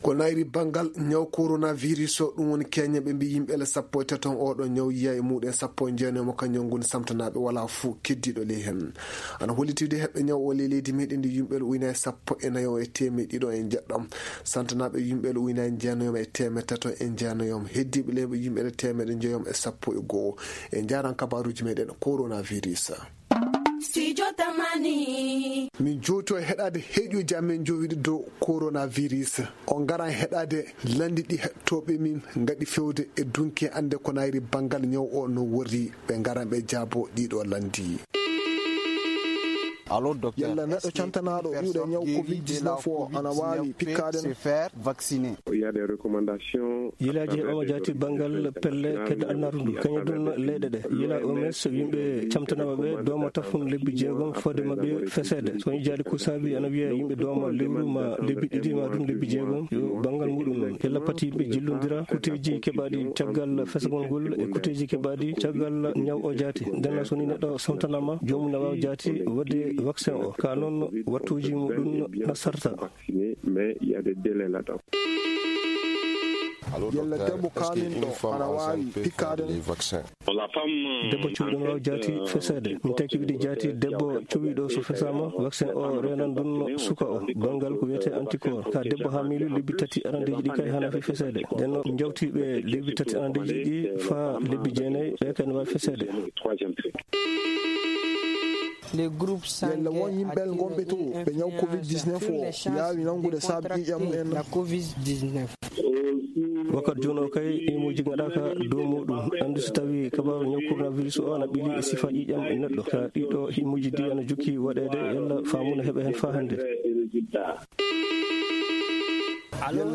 Aujourd’hui, nous vous proposons des questions posées par des auditeurs. Elles sont répondues par des spécialistes de la santé.